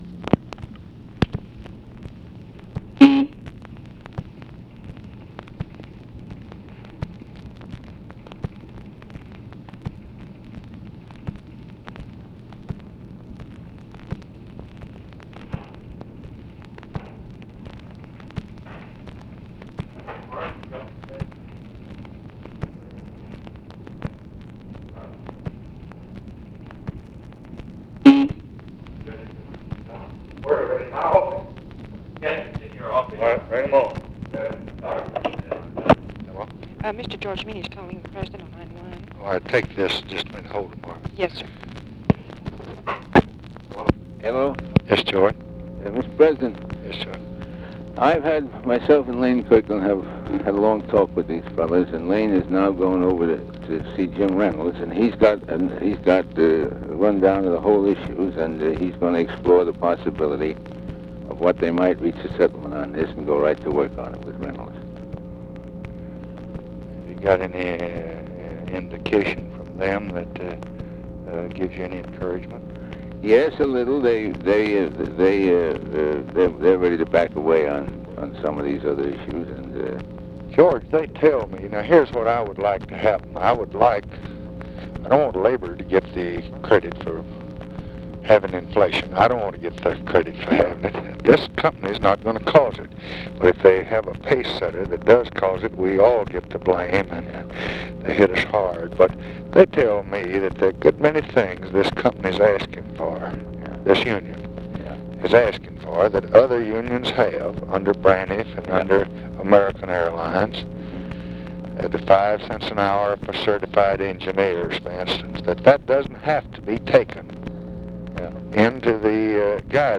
Conversation with GEORGE MEANY, OFFICE CONVERSATION and OFFICE SECRETARY, July 27, 1966
Secret White House Tapes